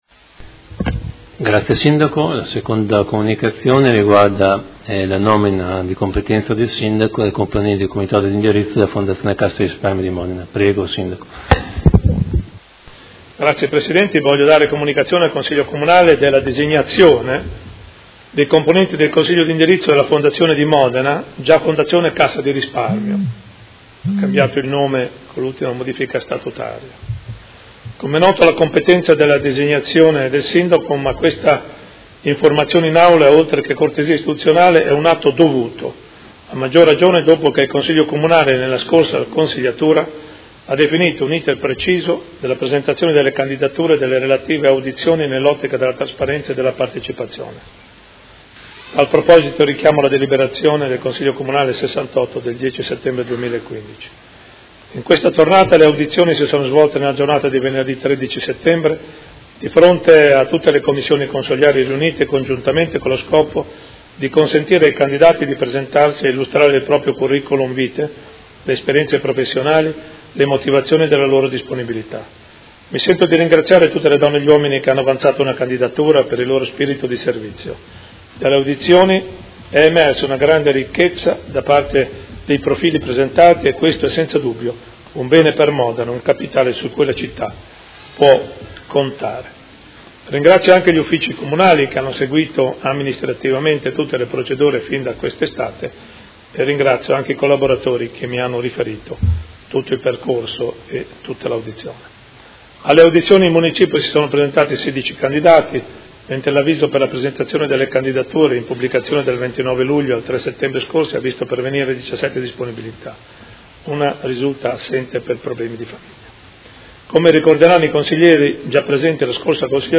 Seduta del 26/09/2019. Comunicazione del Sindaco su nomina Fondazione Cassa di Risparmio di Modena